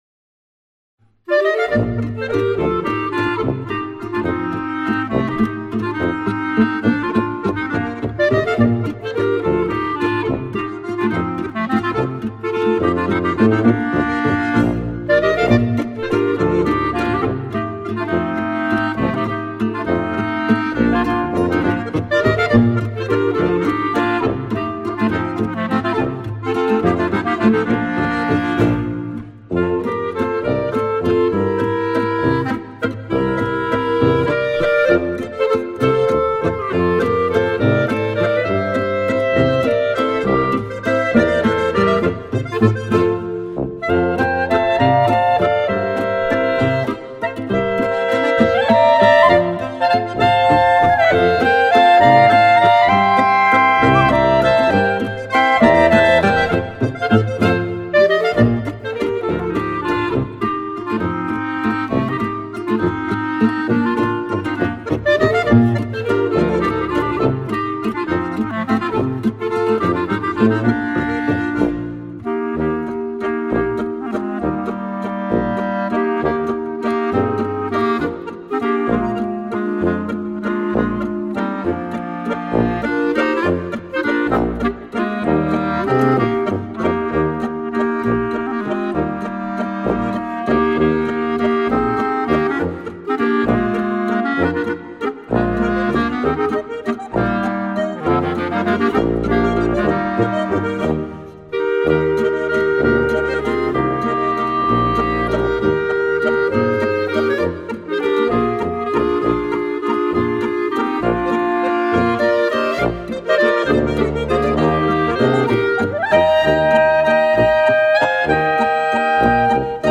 Gattung: Volksmusikstücke
Besetzung: Volksmusik/Volkstümlich Weisenbläser
für 2 oder 3 Klarinetten in Bb (incl. Melodiestimmen in C)
und Bassinstrument in C oder Bassklarinette in Bb